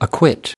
Transcription and pronunciation of the word "acquit" in British and American variants.